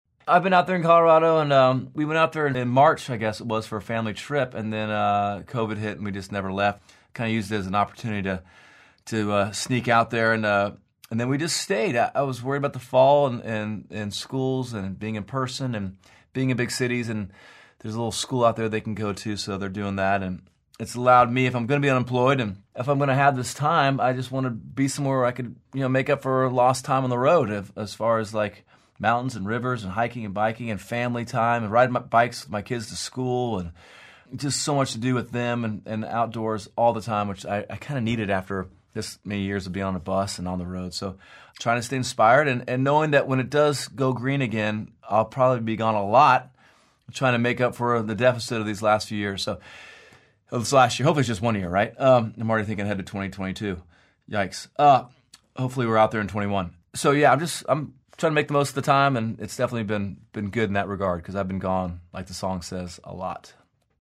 Audio / Dierks Bentley says he and his family are enjoying living out in Telluride, while riding out the pandemic.